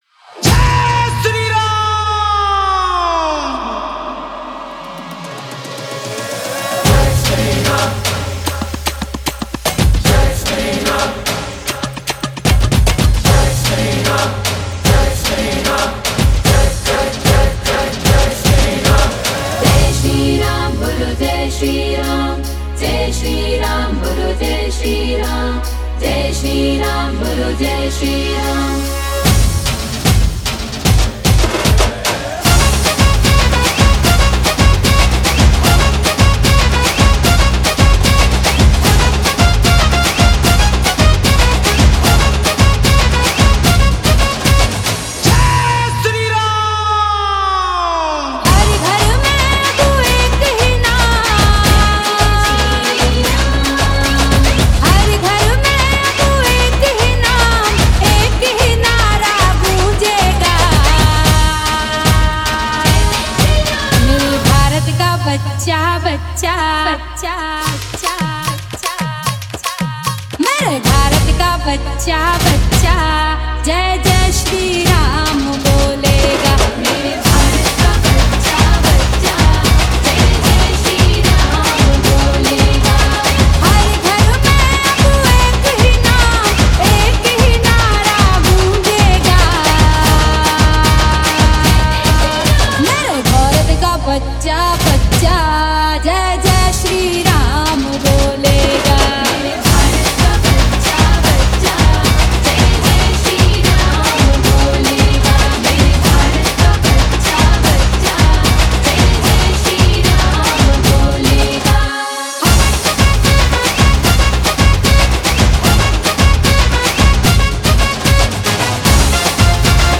Bhakti DJ Remix Songs